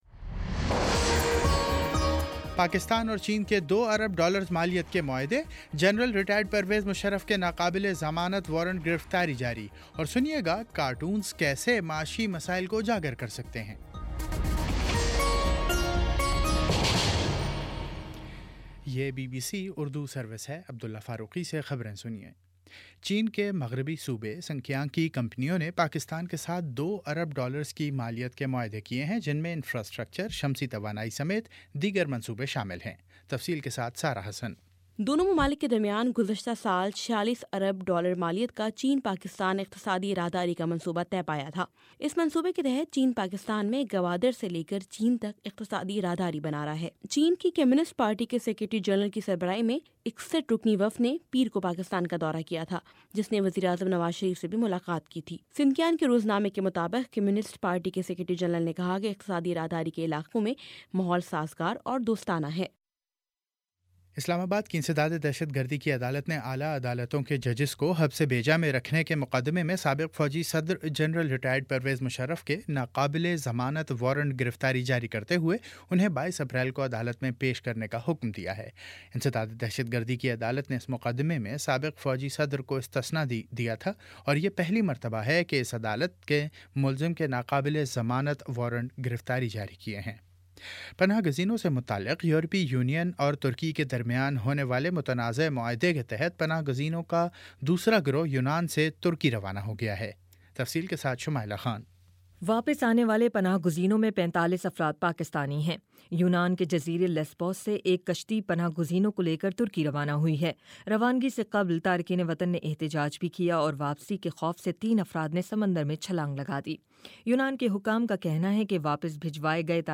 اپریل 08 : شام پانچ بجے کا نیوز بُلیٹن